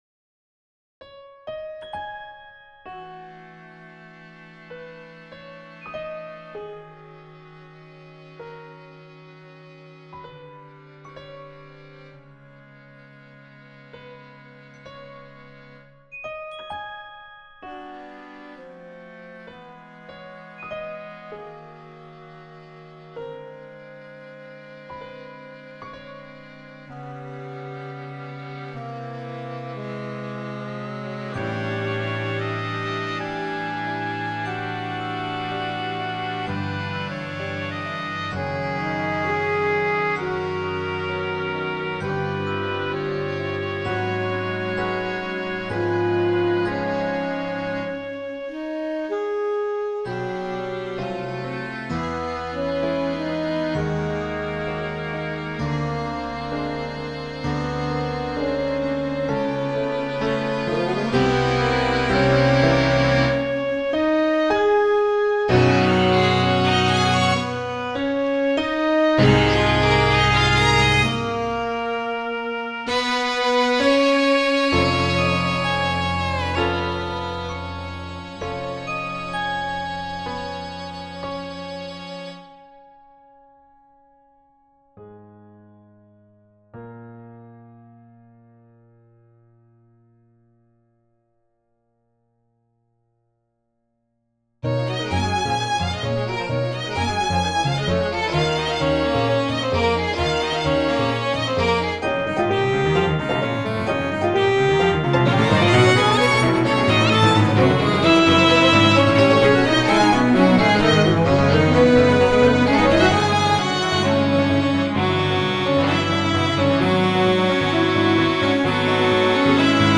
this is a vocal work for soprano, oboe, tenor sax., celesta, piano, violin, viola, and cello.